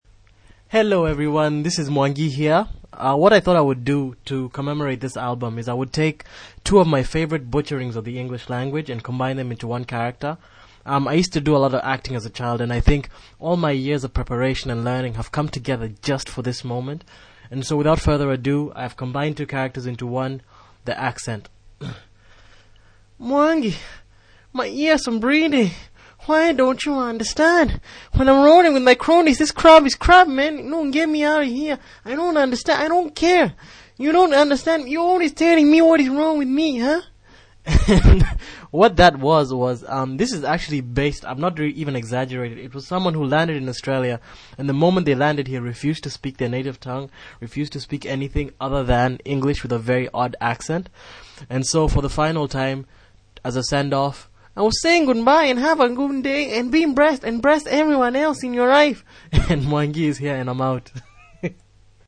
icon for podpress  Bad Imitation of a Wenging Person [1:02m]: Play Now | Play in Popup | Download
the accent.mp3